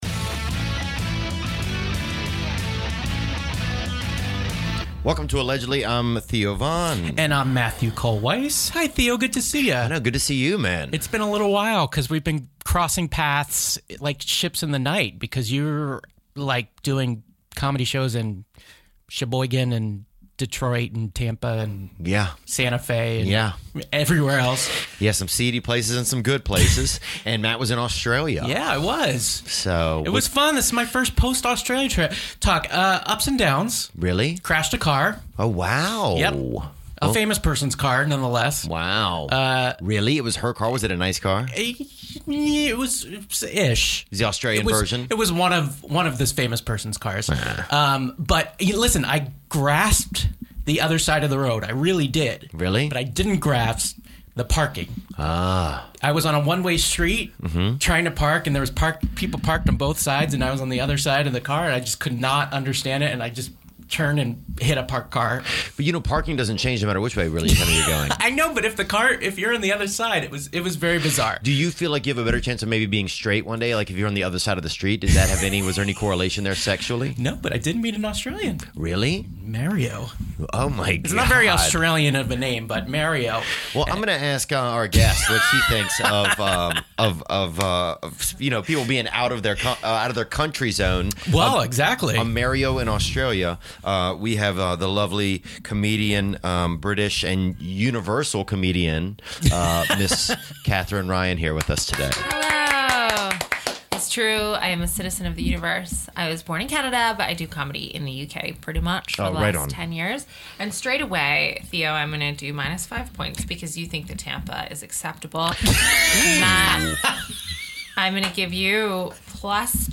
Comedian, writer, tv personality Katherine Ryan drops by the studio with discussions on life in the UK, meeting the Royal family(Duke of I’ve just Cambridge) and why she sides with the Mafia....